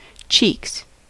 Ääntäminen
Synonyymit bum Ääntäminen US Haettu sana löytyi näillä lähdekielillä: englanti Käännöksiä ei löytynyt valitulle kohdekielelle.